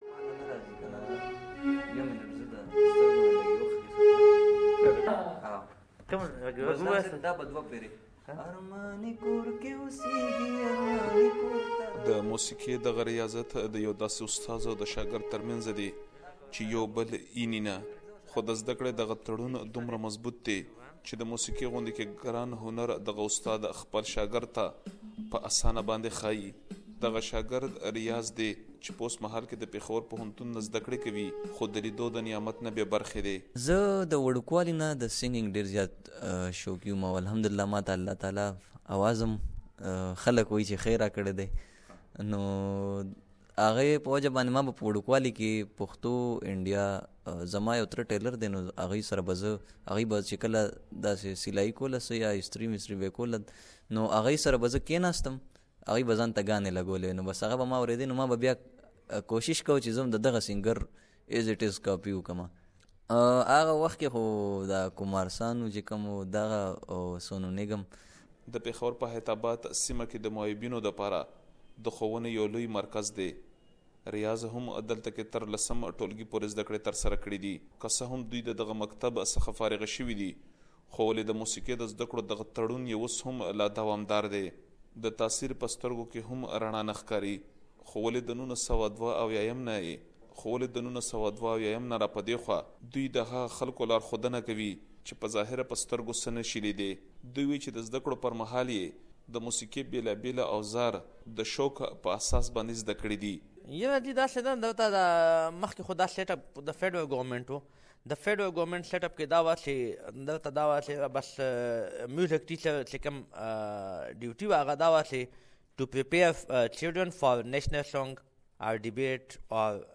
SBS Pashto